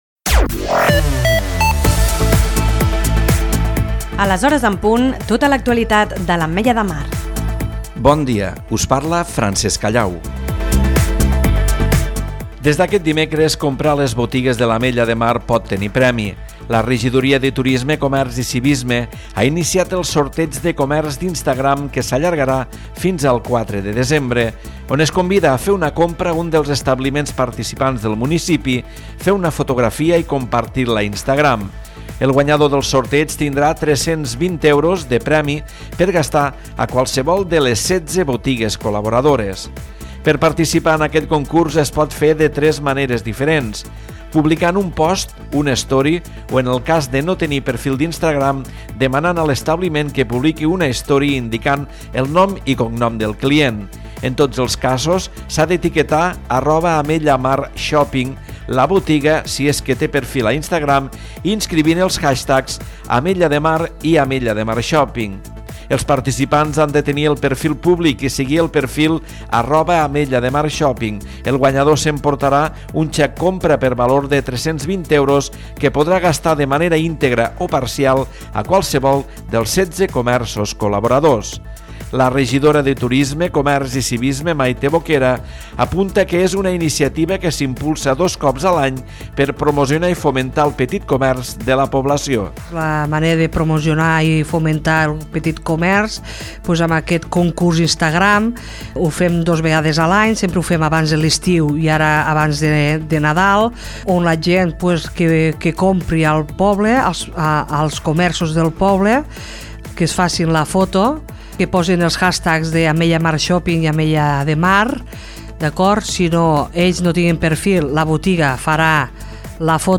Navegació d'entrades Butlletí Informatiu 23/11/2022 Releated Posts Butlletí Informatiu 17/04/2026 La Cala es juga la categoria al camp del Perelló￼ Doble cita cultural de l’Ateneu Calero al Centre d’Interpretació de la Pesca